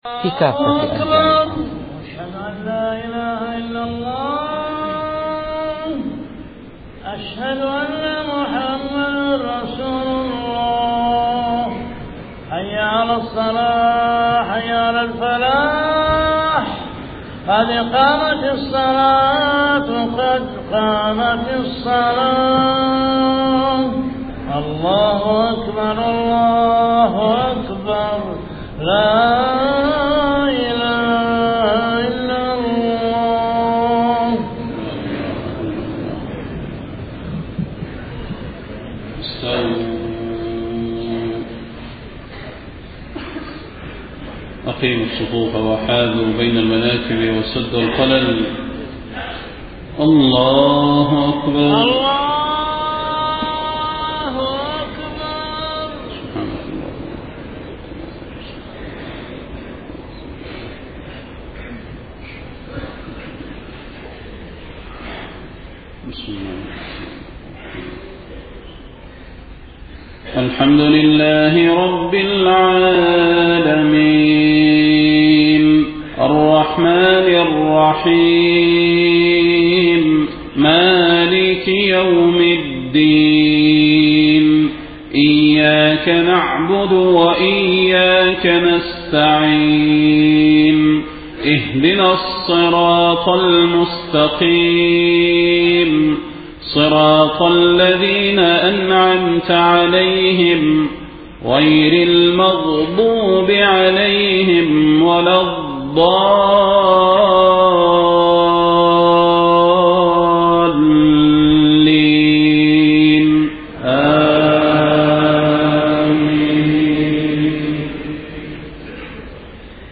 صلاة الفجر 1 محرم 1431هـ سورتي النازعات و الانفطار > 1431 🕌 > الفروض - تلاوات الحرمين